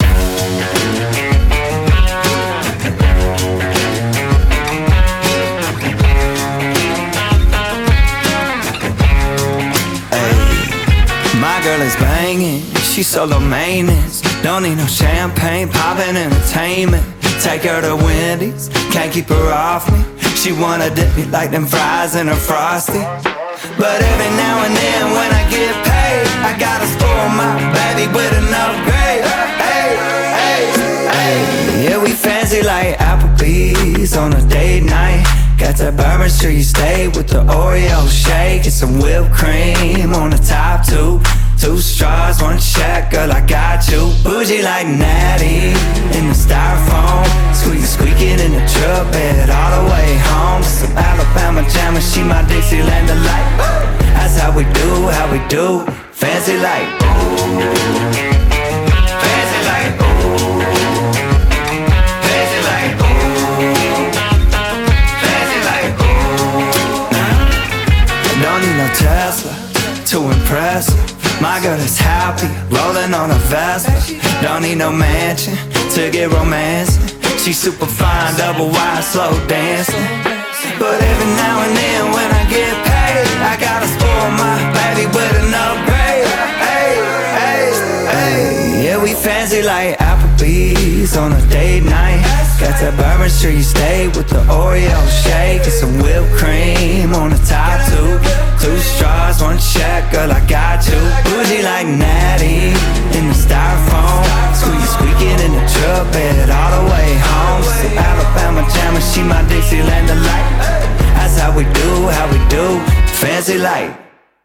BPM160
Audio QualityMusic Cut